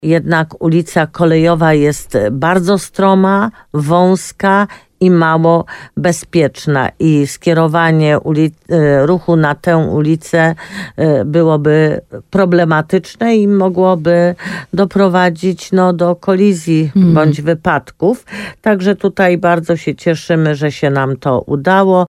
– Udało się wstrzymać zamknięcie jednej z dróg, której objazd w okresie zimowym byłby niebezpieczny – mówi Jolanta Juszkiewicz, burmistrz Limanowej.
W programie Słowo za Słowo na antenie RDN Nowy Sącz, podkreślała, że ulica Kolejowa jest jedyną alternatywą dla ulicy Paderewskiego.